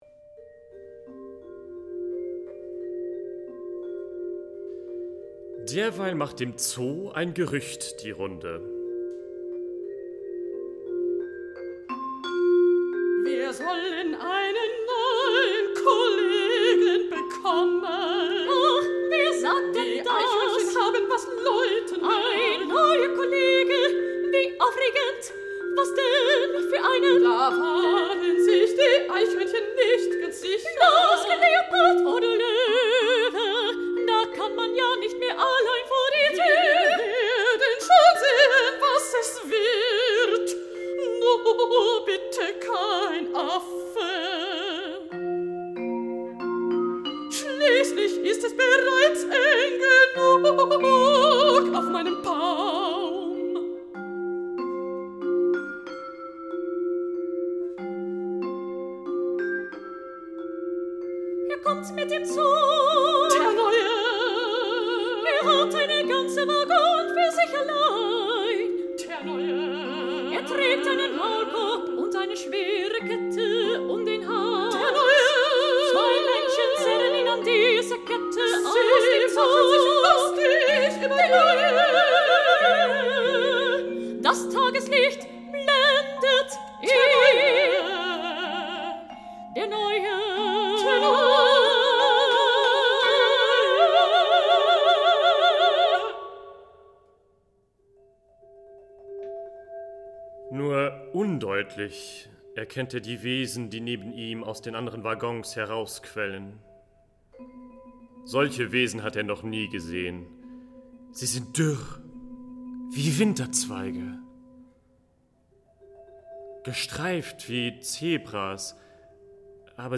Komponist Edzard Locher vertont dieses mehrfach ausgezeichnete Theaterstück für Stimme und Perkussion.